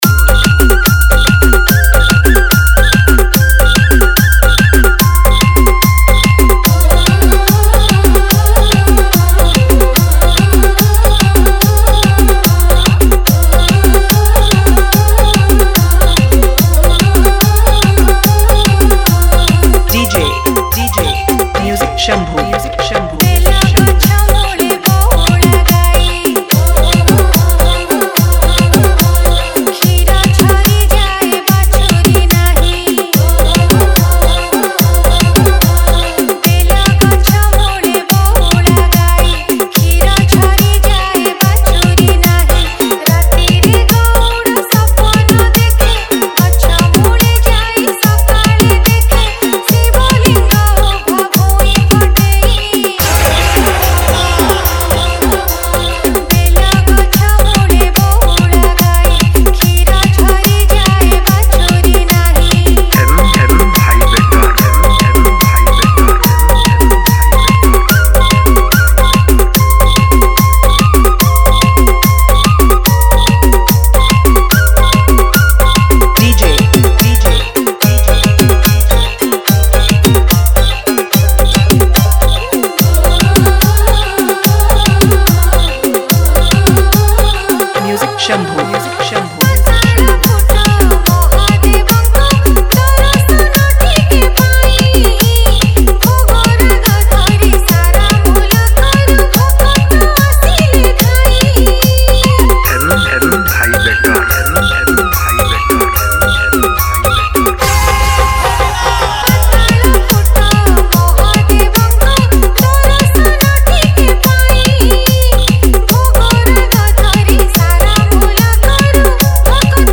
Category:  Odia Bhajan Dj 2019